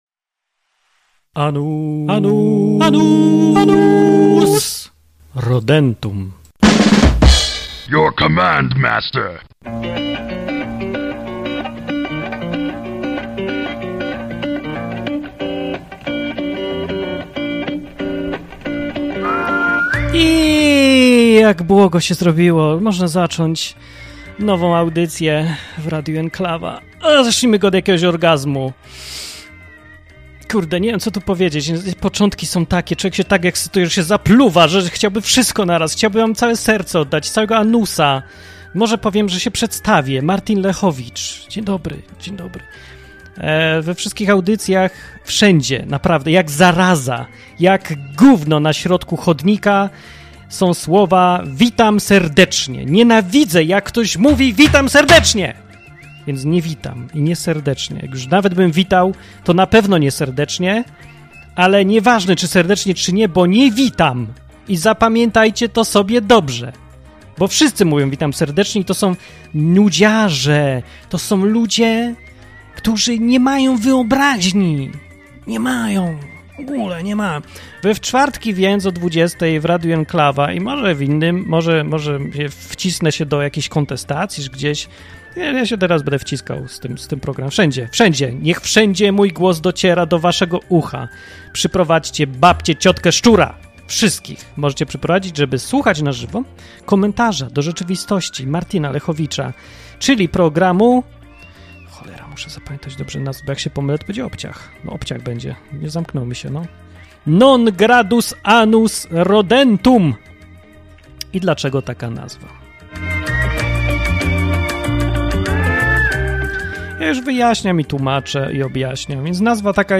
"Non gradus anus rodentum" mówimy wtedy, kiedy chcemy powiedzieć "mam to w dupie" bez ściągania krawata.